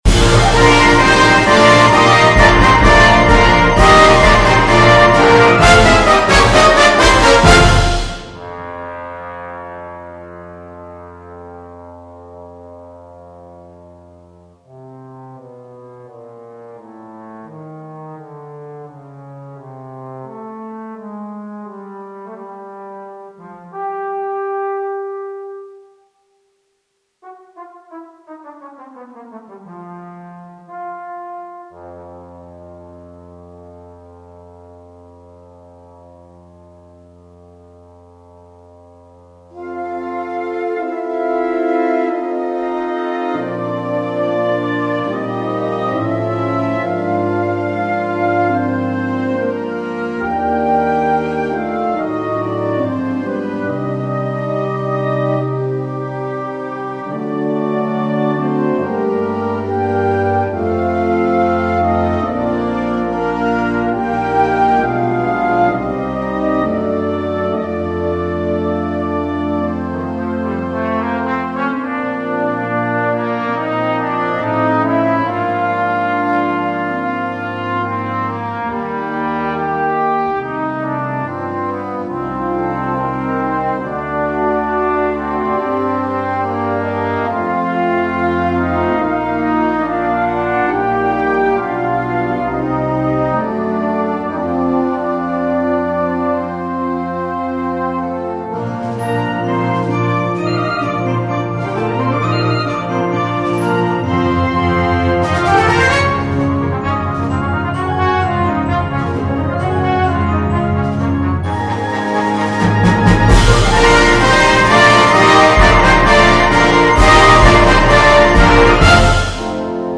per trombone e banda